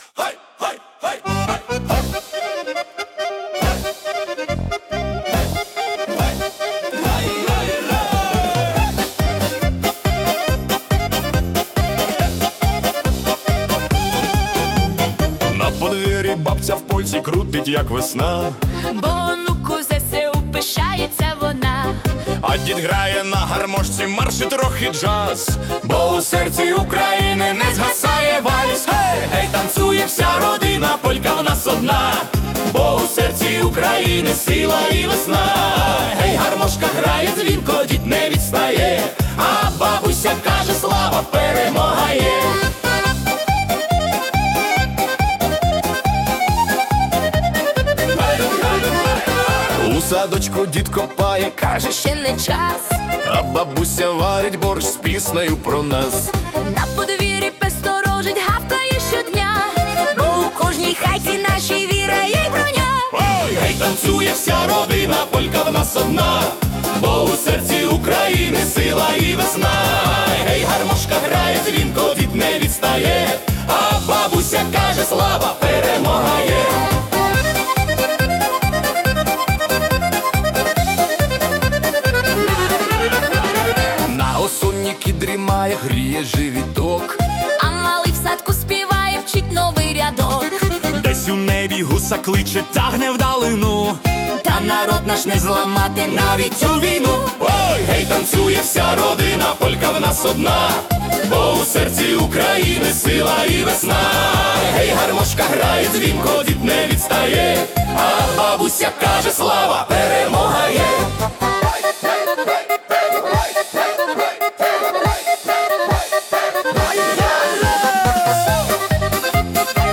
🎵 Жанр: Ukrainian Folk Polka